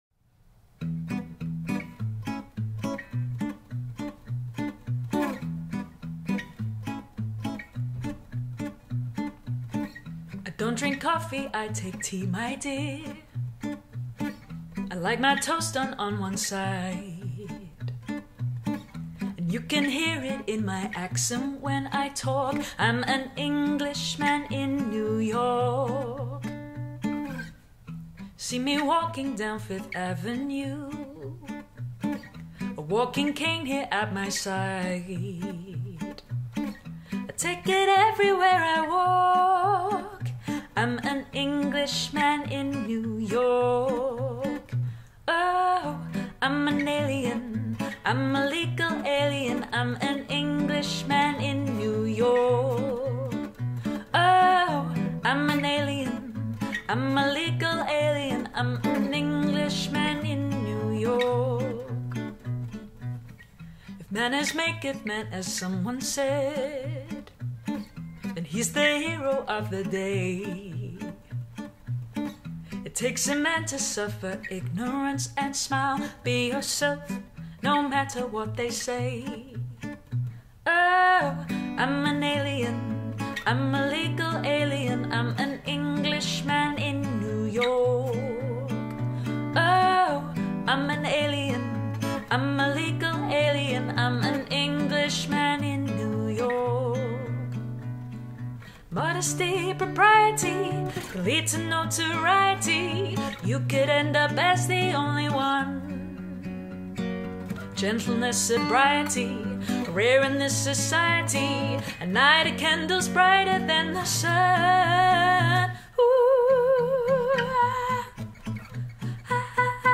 با صدای زن